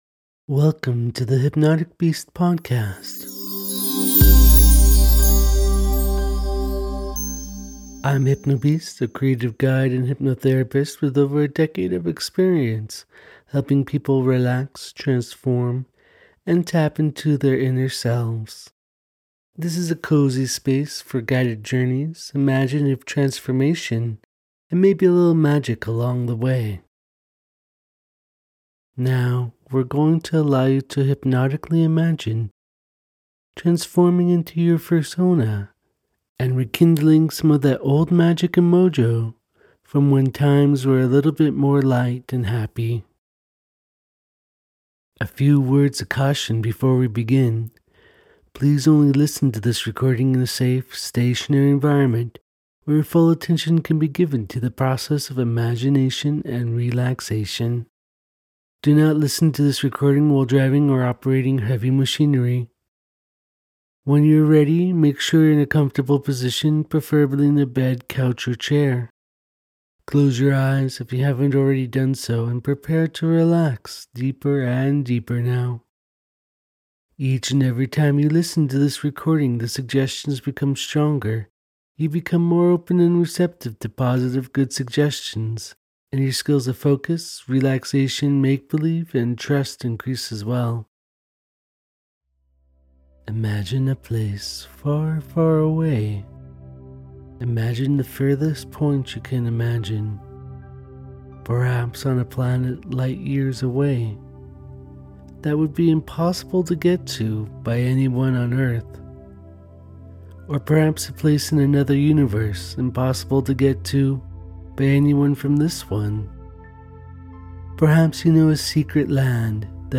This guided imagery track begins with a journey to a sacred furry temple. Then, at the center of the temple, you’ll receive a gentle progressive relaxation and a steady count-down, easing body and mind into deep calm. From there, you’re invited to transform fully into your fursona.